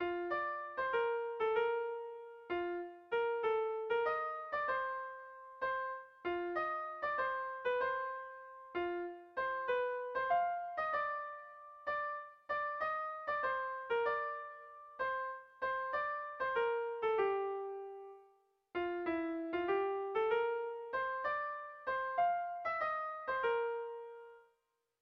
Bertso melodies - View details   To know more about this section
Zortziko txikia (hg) / Lau puntuko txikia (ip)
Rythme